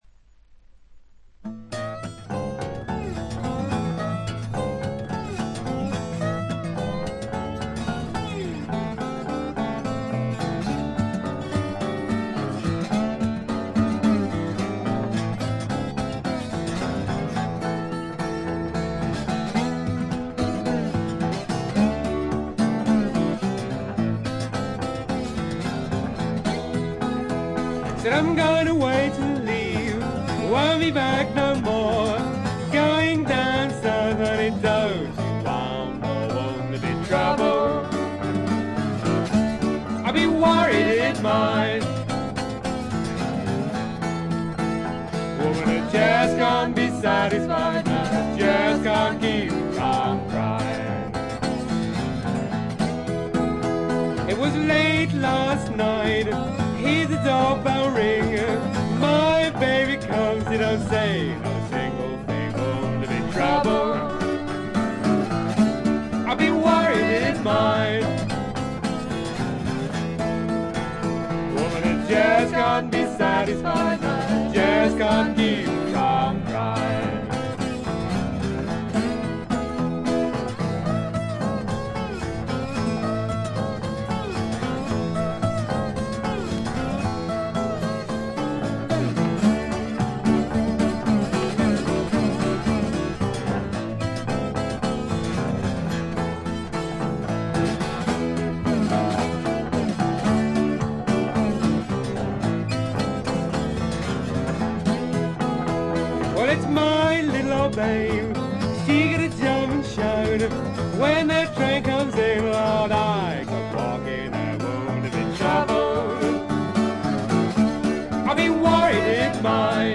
特に気になるノイズはなく極めて良好に鑑賞できると思います。
試聴曲は現品からの取り込み音源です。
Recorded 1977 at Riverside Studio, London
vocal, guitar, bottleneck guitar
guitar, banjo
mandolin, fiddle